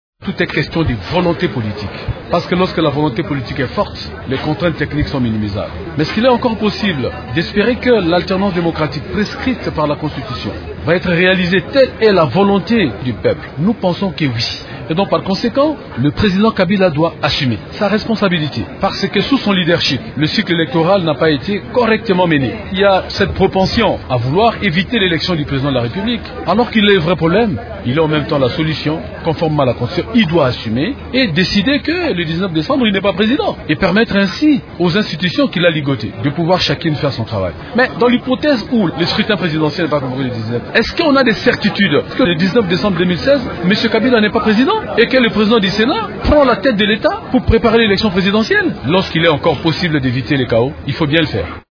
Vous pouvez écouter les explications du porte-parole du Front des démocrates.